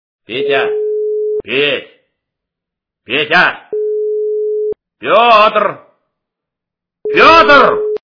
» Звуки » Именные звонки » Именной звонок для Петра - Петя, Петь, Петя, Петр, Петр
При прослушивании Именной звонок для Петра - Петя, Петь, Петя, Петр, Петр качество понижено и присутствуют гудки.